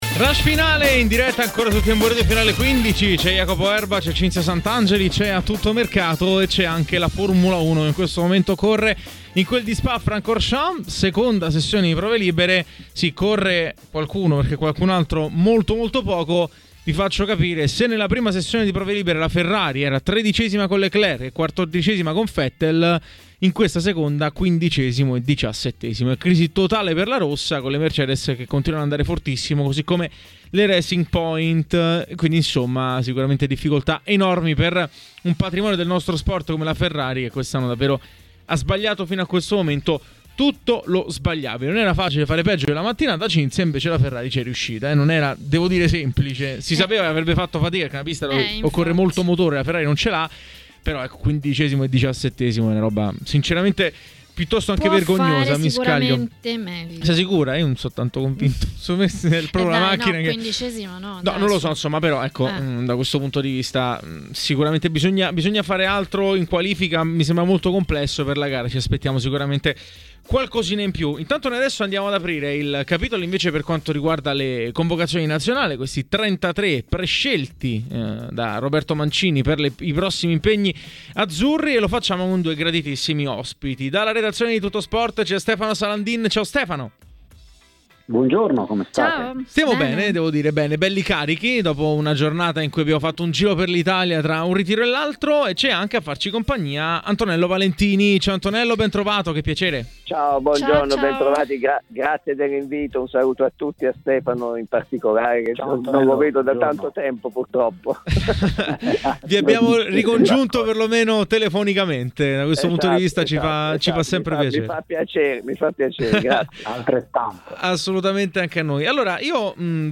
Ha parlato ai microfoni di TMW Radio